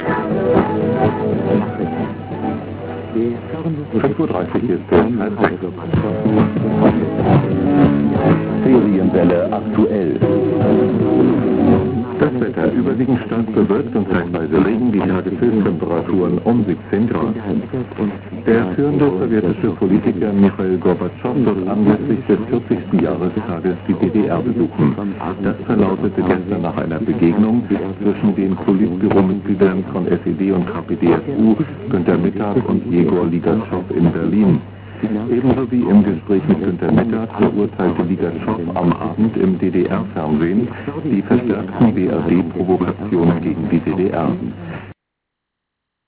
holidaymakers produced at Rostock.